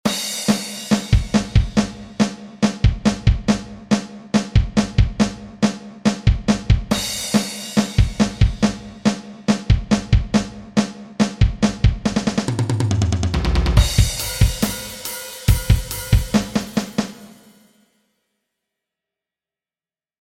Schlagzeugsolo für Anfänger Teil 12
Heute zum Thema Flams. Hier der Anfang des Solos.
schlagzeugsolo_fur_anfanger_teil_12.mp3